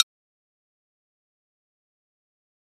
フリー効果音：クリック
クリックの音です！パソコンの操作やコンピューターを使うシーンにぴったり！
click.mp3